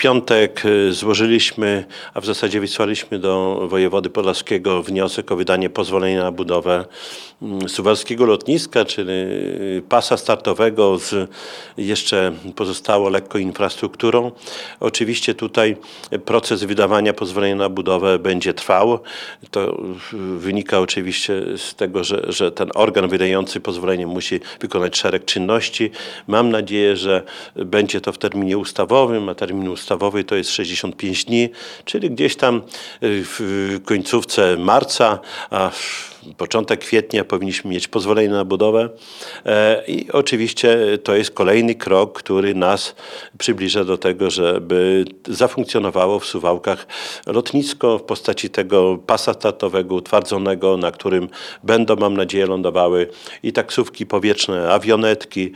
– Decyzji można spodziewać się w kwietniu tego roku – powiedział Radiu 5 Czesław Renkiewicz, prezydent Suwałk.